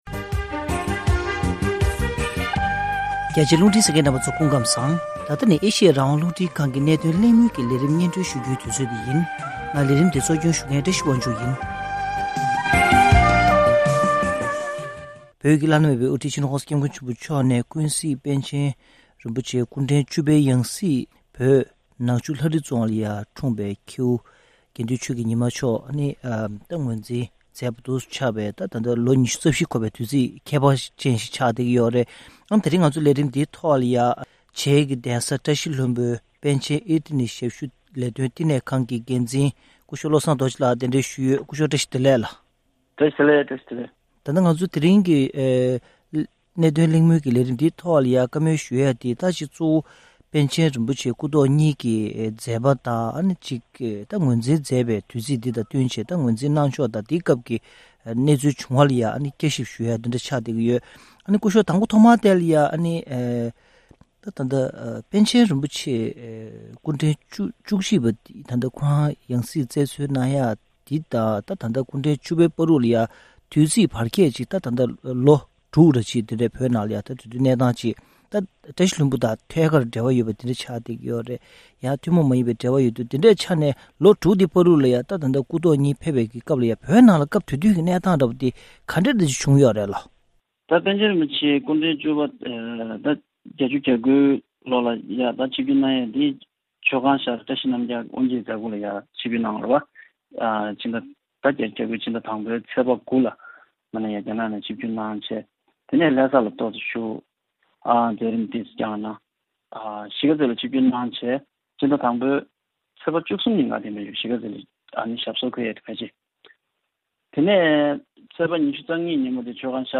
༄༅༎ཐེངས་འདིའི་གནད་དོན་གླེང་མོལ་གྱི་ལས་རིམ་ནང་།